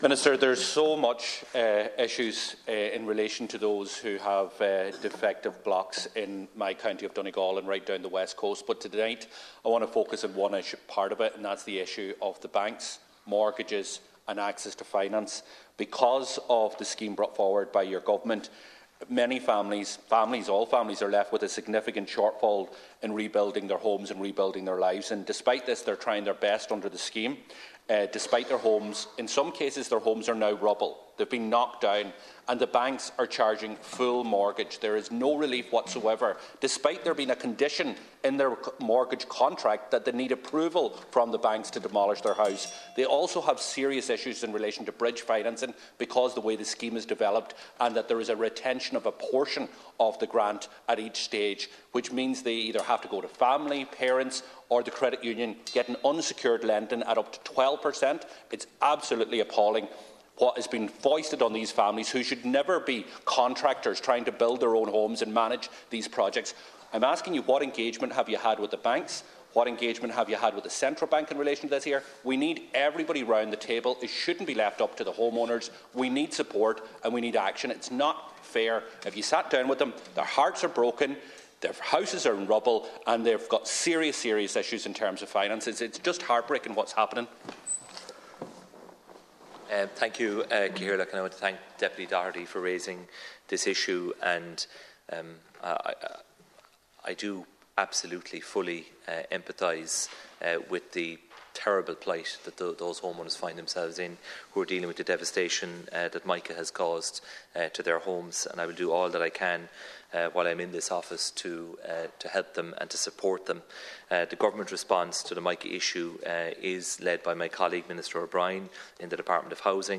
That’s what Donegal Deputy Pearse Doherty told Finance Minister Michael McGrath when calling on him to impress on the banks the need for support for affected homeowners.